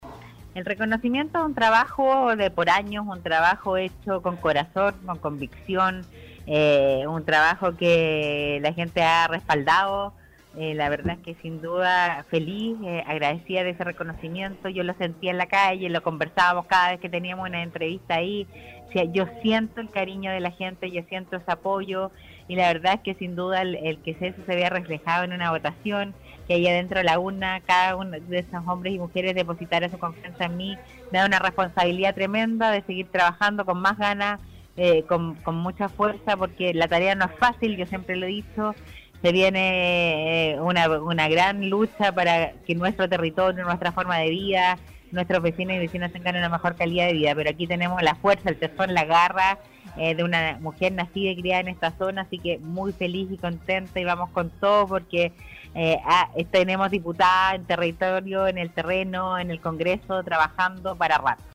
En entrevista con el programa Punto de Vista